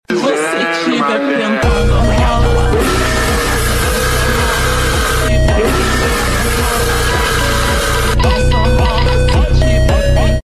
Angel ahh robot sound effects free download